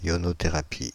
Ääntäminen
France (Île-de-France): IPA: /jɔ.nɔ.te.ʁa.pi/